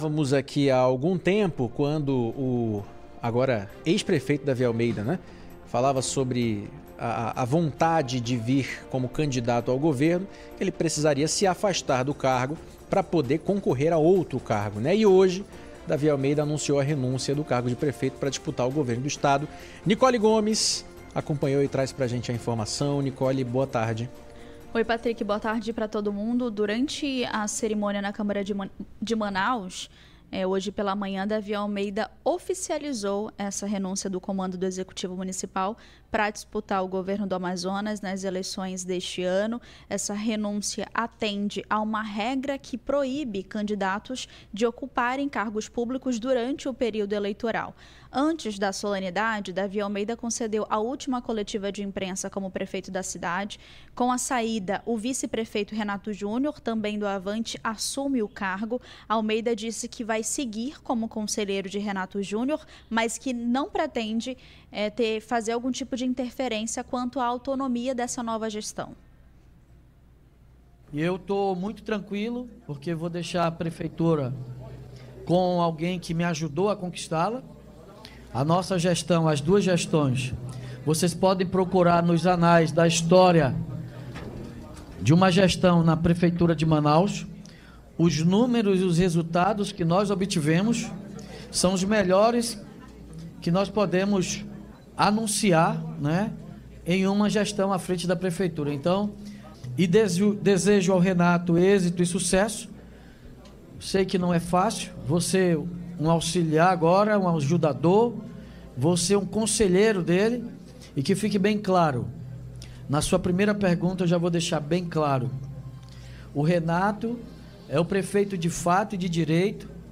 Em discurso emocionado na Câmara Municipal de Manaus, o ex-prefeito citou a Bíblia e diz que será "conselheiro". A saída atende regra eleitoral e efetiva o vice no comando do município.